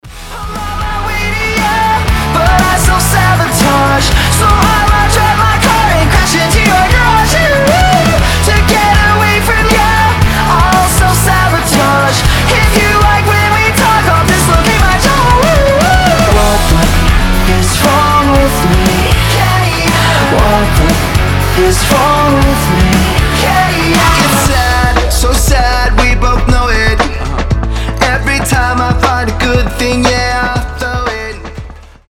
• Качество: 320, Stereo
громкие
Драйвовые
Alternative Rock
бодрые